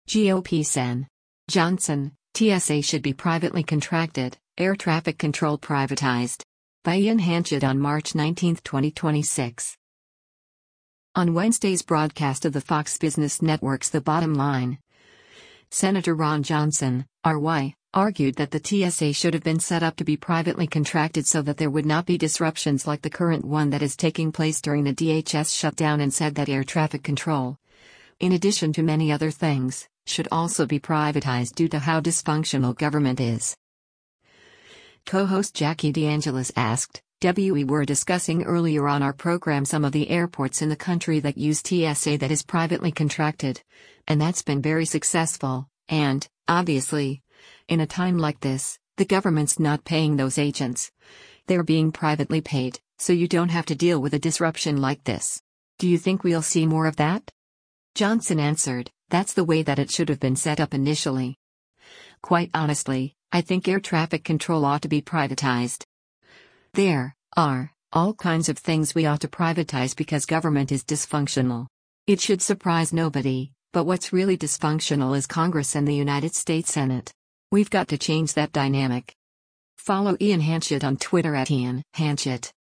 On Wednesday’s broadcast of the Fox Business Network’s “The Bottom Line,” Sen. Ron Johnson (R-WI) argued that the TSA should have been set up to be privately contracted so that there would not be disruptions like the current one that is taking place during the DHS shutdown and said that air traffic control, in addition to many other things, should also be privatized due to how dysfunctional government is.